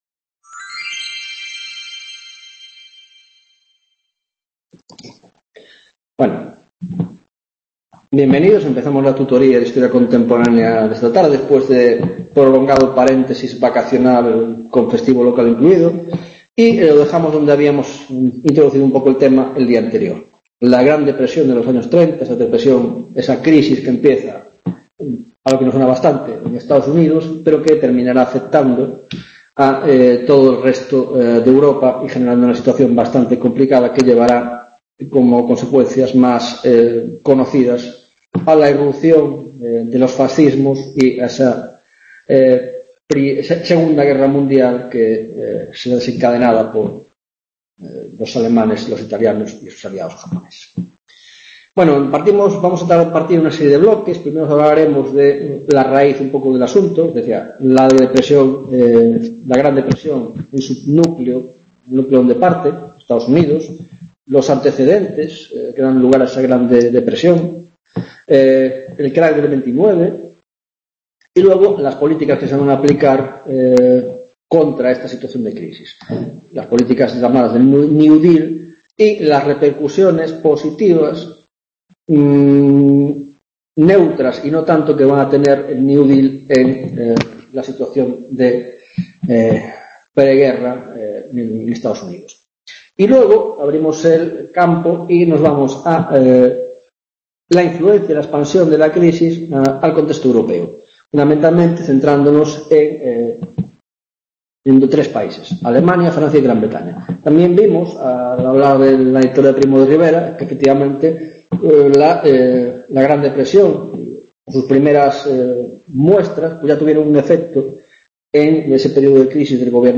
21ª tutoria de Historia Contemporánea - Depresión de los Años 30